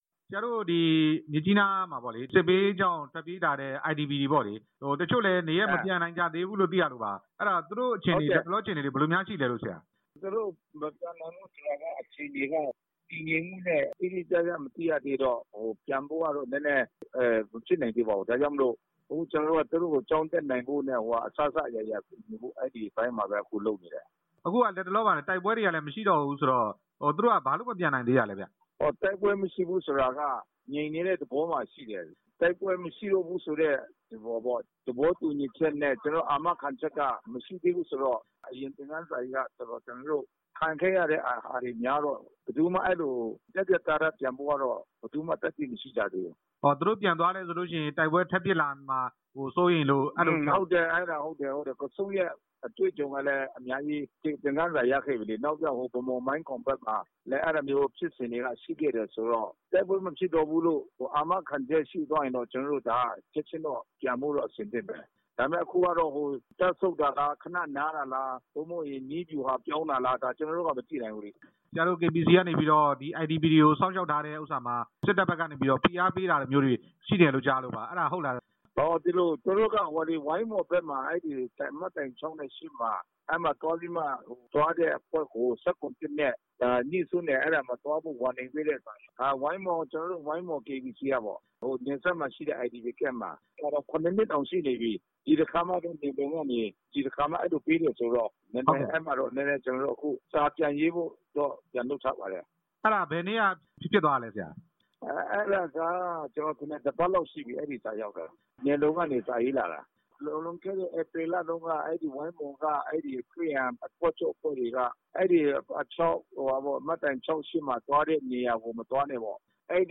ဝိုင်းမော် KBC အဖွဲ့ကို နယ်စပ်ရေးရာဝန်ကြီးက တားမြစ်တဲ့အကြောင်း မေးမြန်းချက်